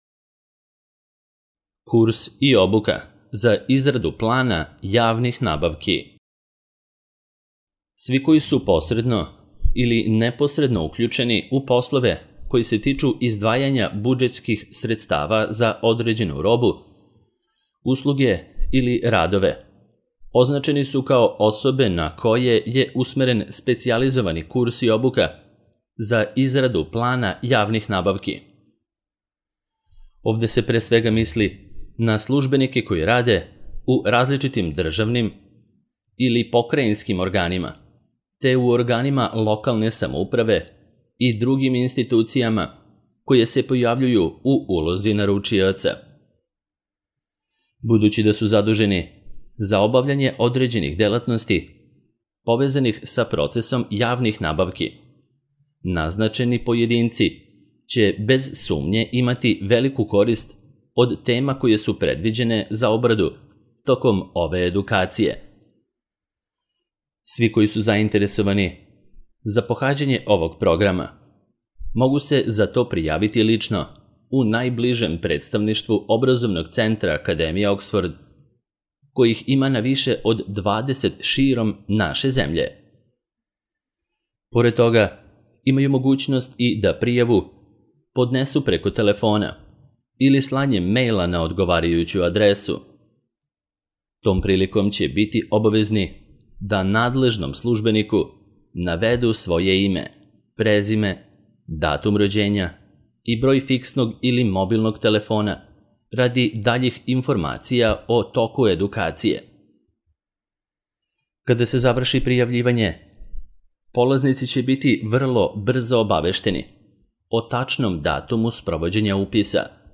Izrada plana javnih nabavki - Audio verzija